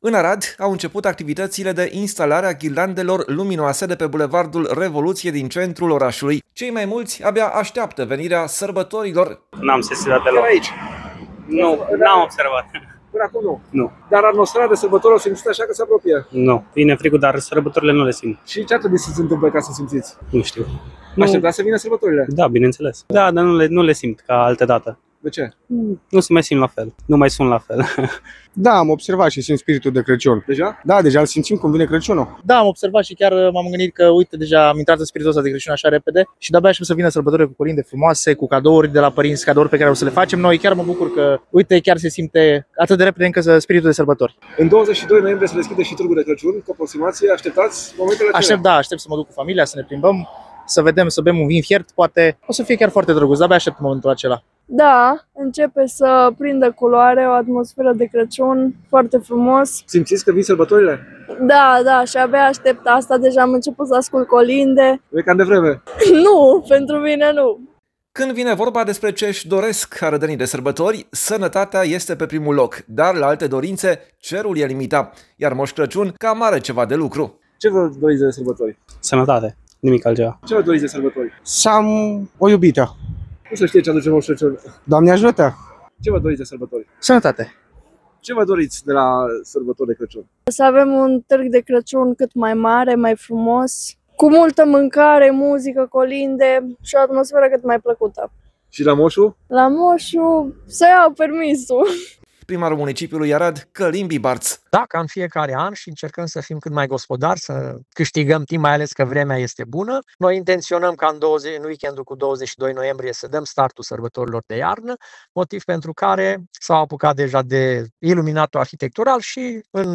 Târgul de Crăciun va avea loc simultan în Piața Catedralei, în Parcul Reconcilierii și în Piața Avram Iancu, spune Călin Bibarț, primarul municipiului Arad.
Abia aștept sărbătorile, am început să ascult deja colinde, pentru mine nu este deloc prea devreme”, spune o tânără.